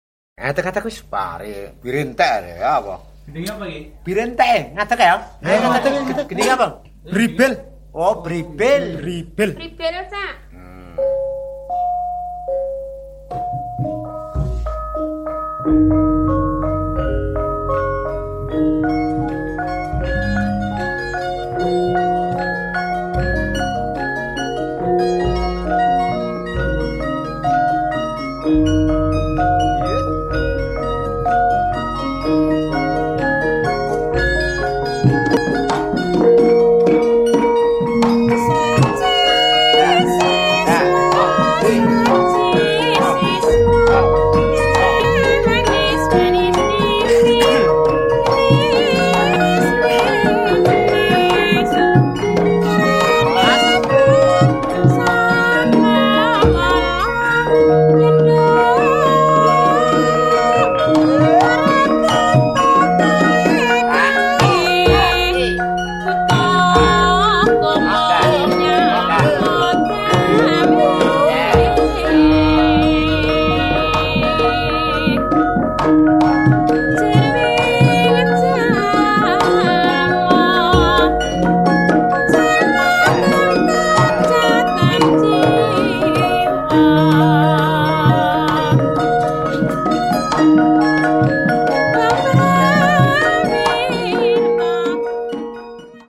とてもクールにして演劇的な側面も持つ心地良い演奏。ゆったりとしたガムランに女性の独特な歌が印象深いです。